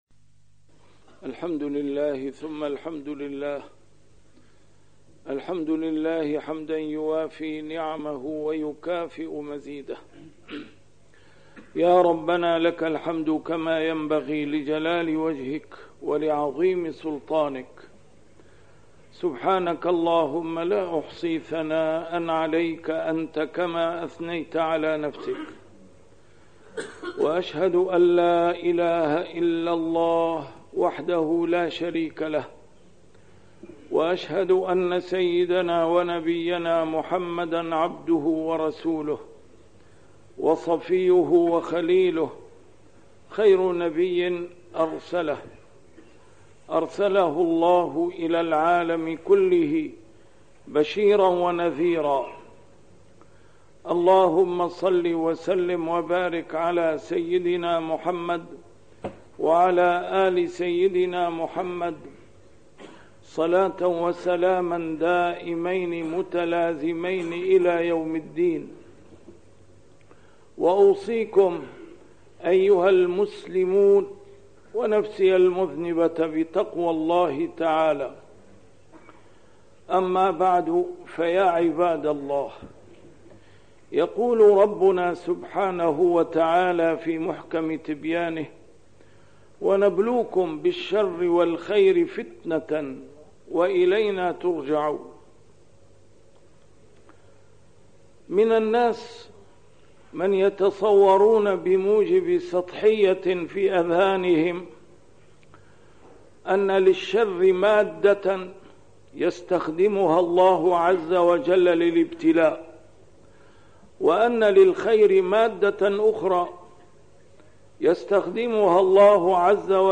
A MARTYR SCHOLAR: IMAM MUHAMMAD SAEED RAMADAN AL-BOUTI - الخطب - رُب نعمةٍ تحولت إلى نقمة!